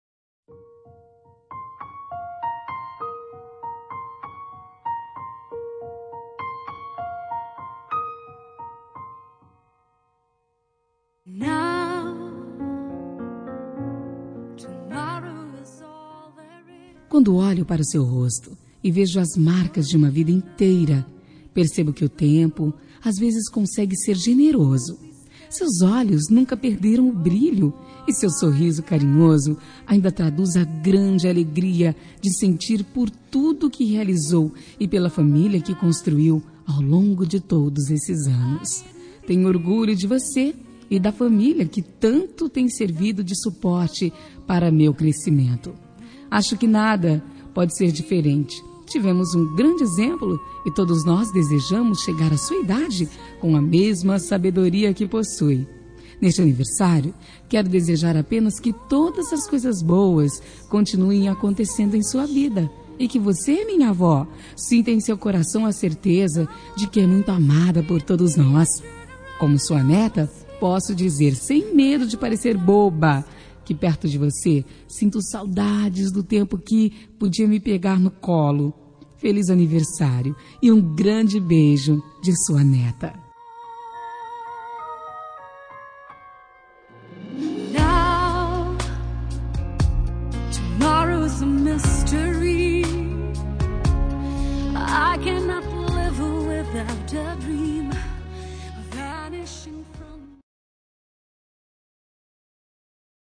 Telemensagem Aniversário de Avó – Voz Feminina – Cód: 2059